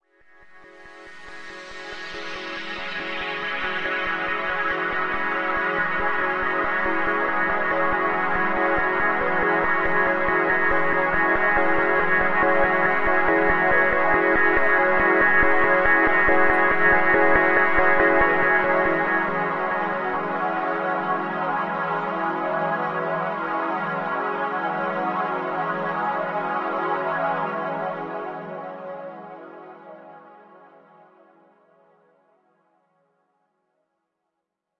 白噪声" whiteenoisewave
描述：使用CoolEdit生成的白噪声。淡入淡出，应用一点回声。
标签： 噪声 空间 斯派西 合成的 白色
声道立体声